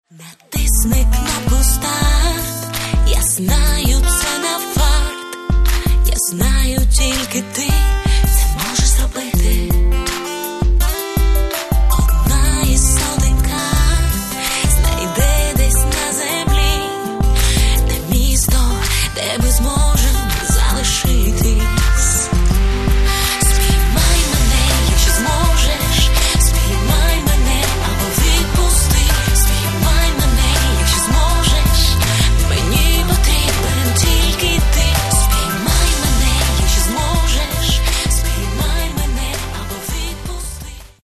Каталог -> Поп (Легка) -> Лірична